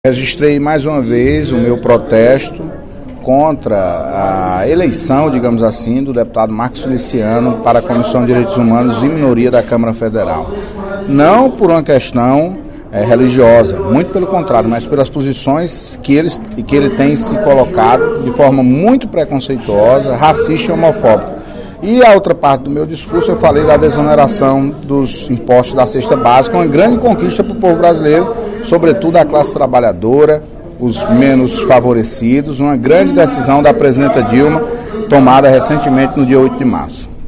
A política de desoneração dos produtos da cesta básica, que passarão a ser isentos de impostos federais, anunciada na última sexta-feira (08/03) pela presidente da República, Dilma Rousseff, foi elogiada pelo deputado Antonio Carlos (PT), em pronunciamento nesta terça-feira (12/03), durante o primeiro expediente.